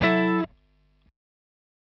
Gbm7.wav